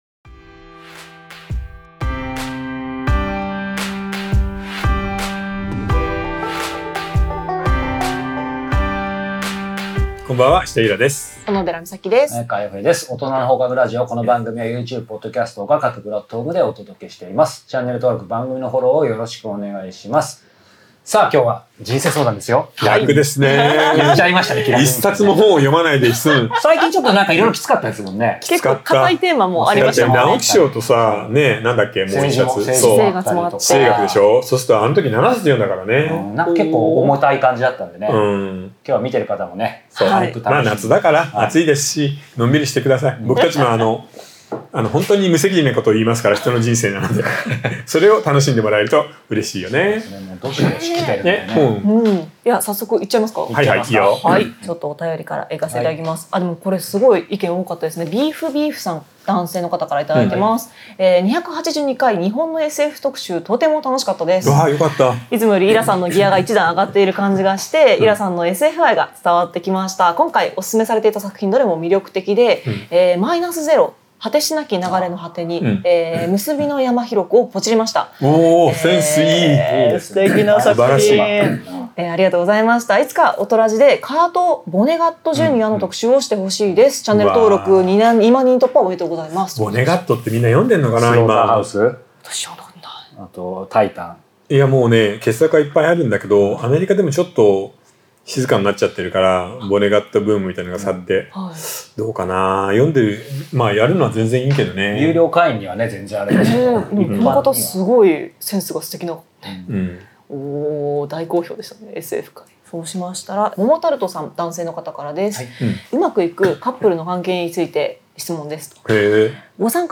真夏の人生相談スペシャル 大量に届いたリスナーのお悩みに３人が全力投球で回答します ・年を重ねる喜びとは何か ・2作目が全く書けません ・好きな異性の部位は？